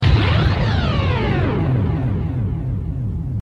Ouça e compartilhe Ultraman Seven Rise. zipper sound. 252 visualizações.